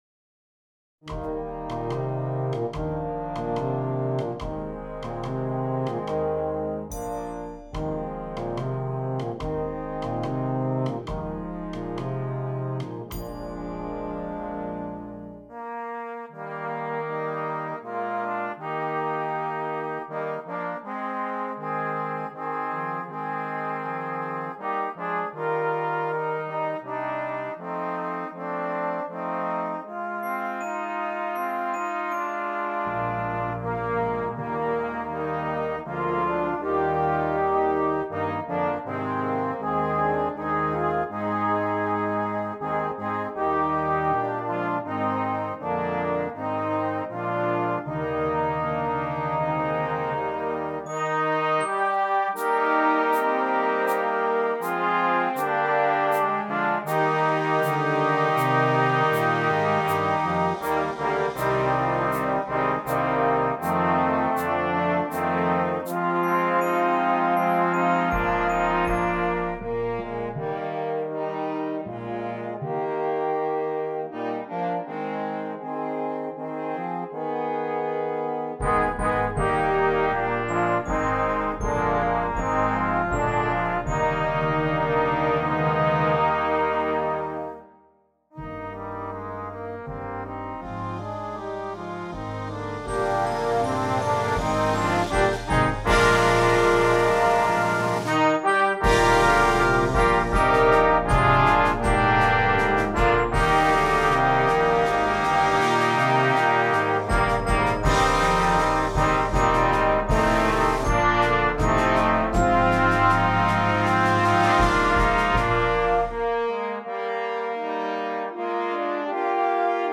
Brass Choir
South African Folk Song